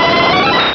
Cri de Voltali dans Pokémon Rubis et Saphir.